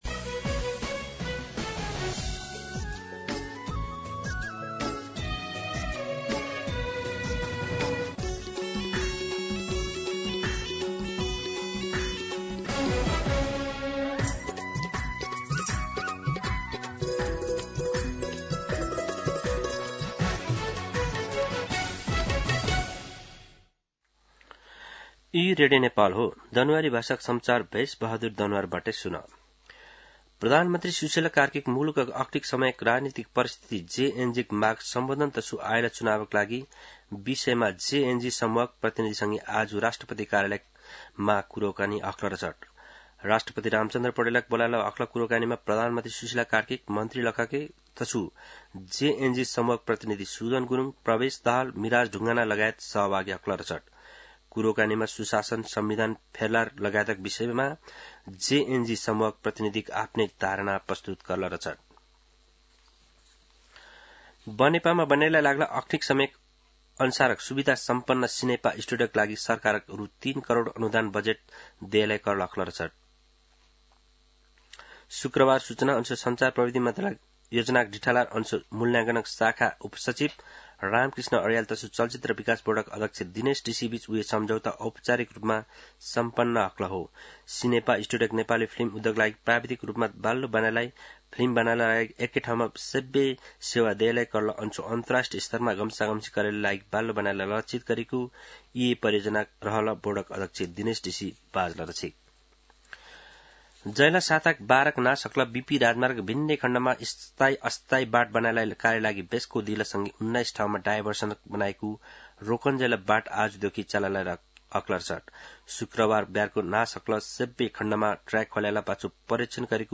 दनुवार भाषामा समाचार : २५ असोज , २०८२
Danuwar-News-2.mp3